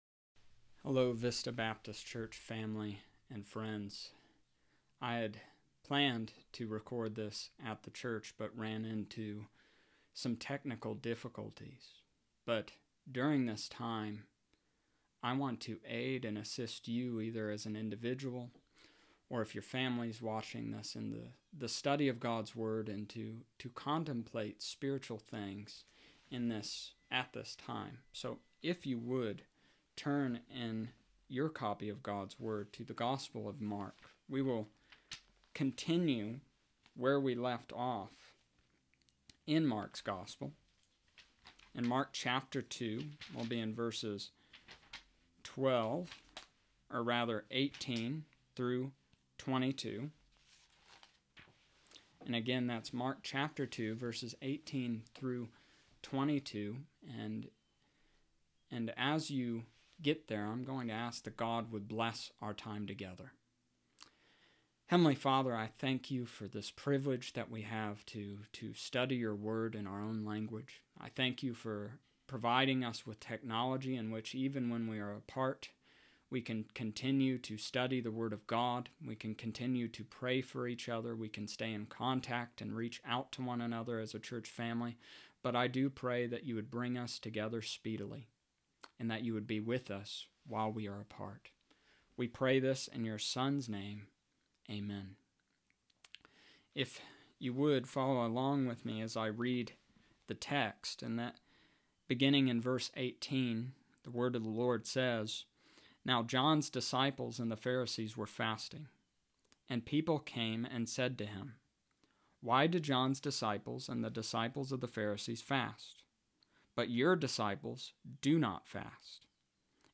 I had planned on recording at the church but technical difficulties required me to do it from my home office.